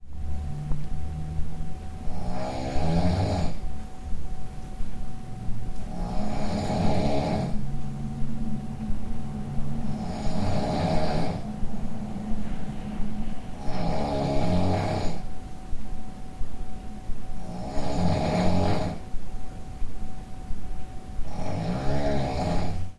Звуки женского храпа
Женщина громко храпит в соседней комнате звук